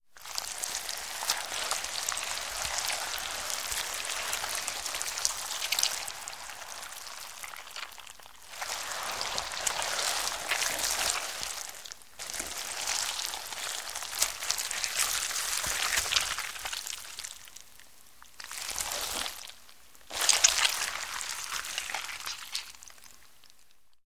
Звуки улитки
Звук улитки медленно ползущей по поверхности